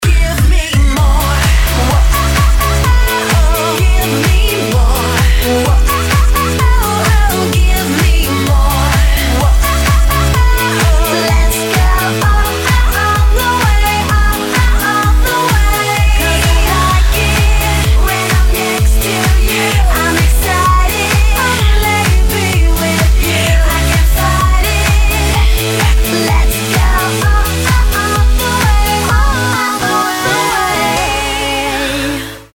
Танцевальные